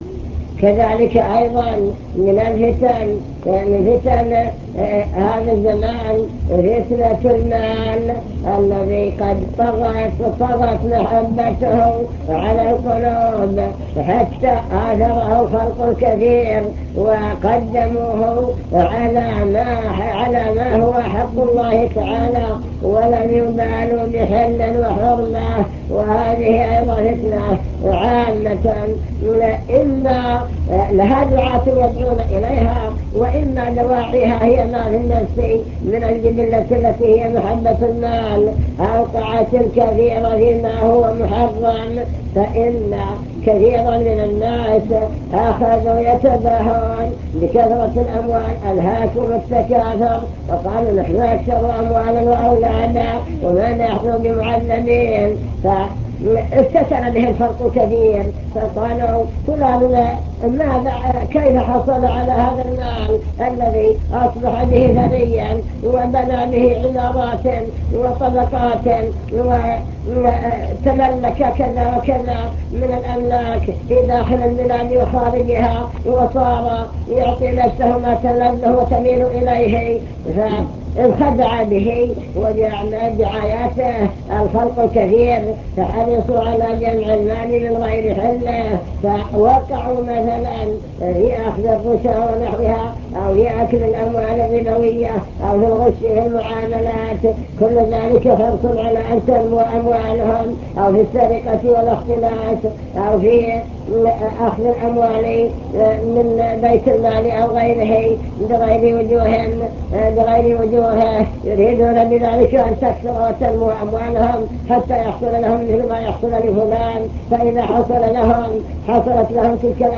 المكتبة الصوتية  تسجيلات - محاضرات ودروس  محاضرة في فتن هذا الزمان ومقاومتها